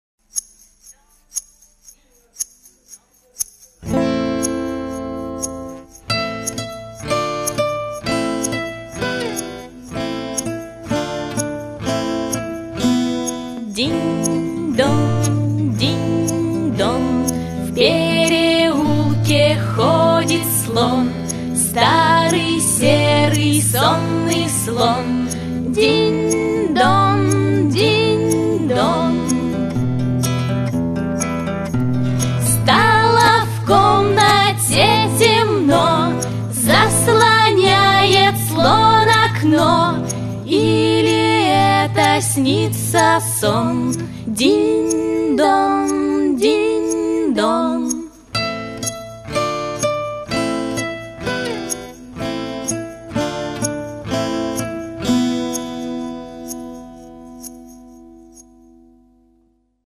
Мюзикл
на сцене дворца детского и юношеского творчества
Сценарий и песни из спектакля: